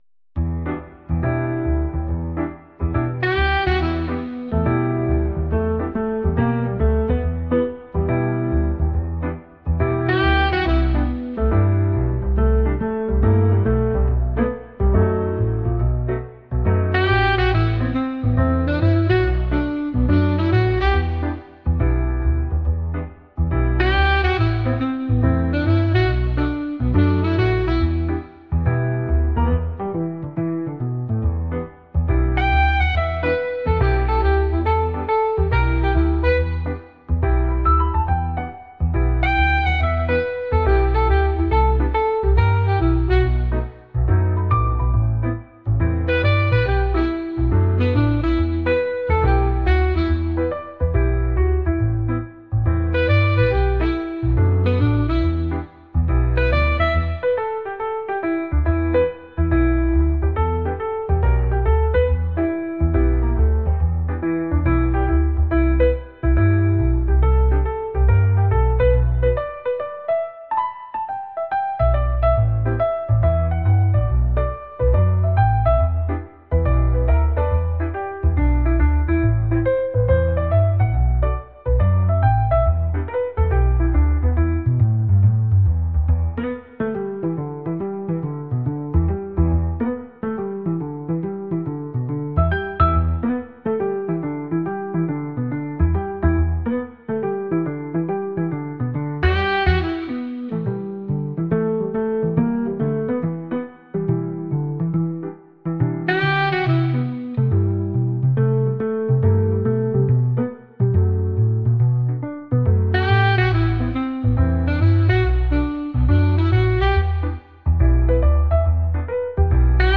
glass ダウンロード 未分類 サックス よかったらシェアしてね！